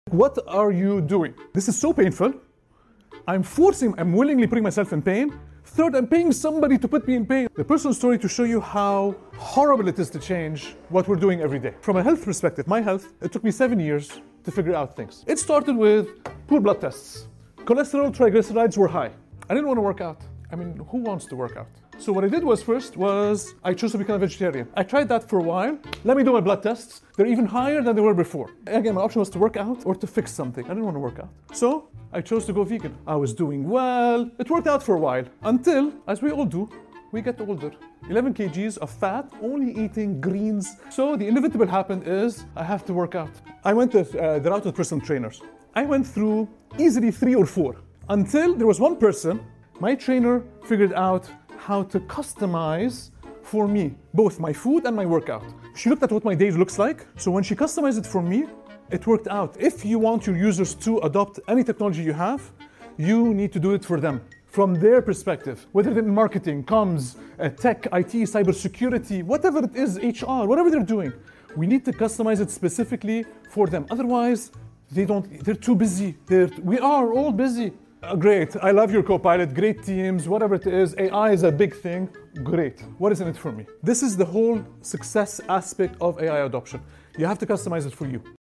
* Trying different sports (including trampoline) * Food changes This extract is from a talk I gave at Dubai AI Week on Change Management in the Workplace. My talk discussed the difficulty of adopting new technologies like AI and how a single perspective shift can change everything.